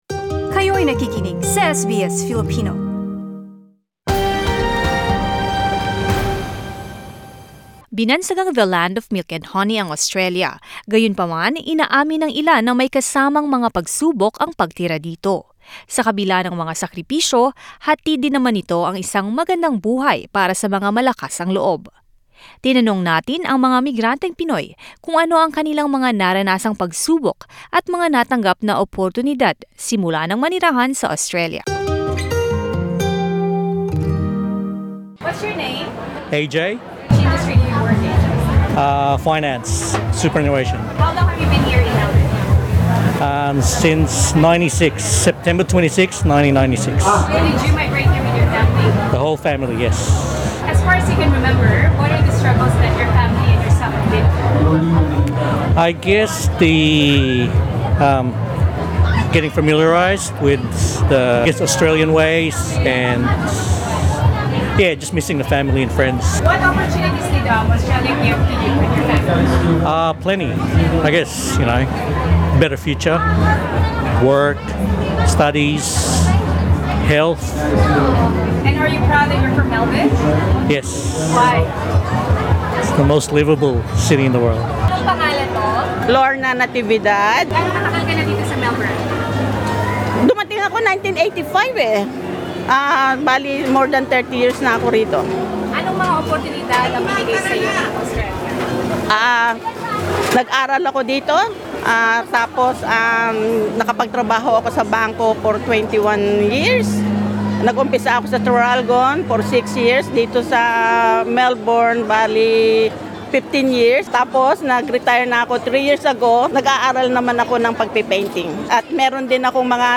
Vox Pop: Anong mga oportunidad ang binigay ng Australya sa'yo?
Filipino migrants share how they overcame struggles as old and new migrants in Australia, and the benefits they are now enjoying.